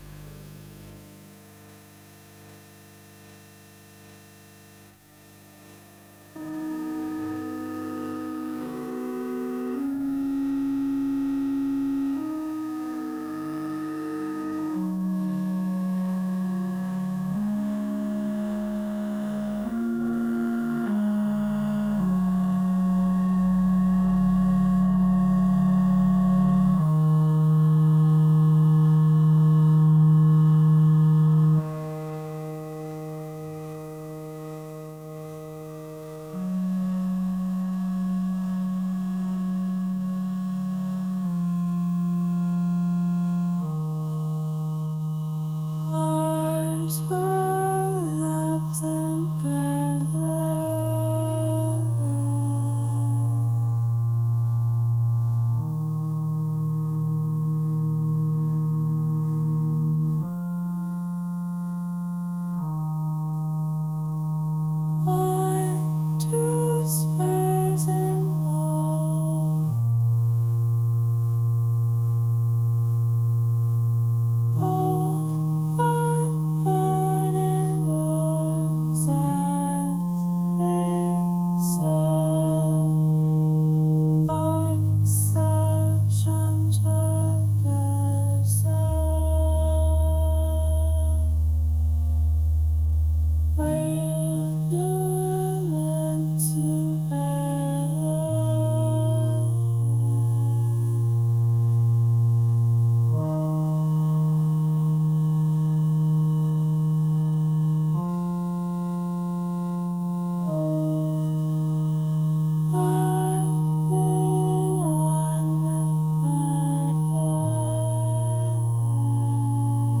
ethereal | pop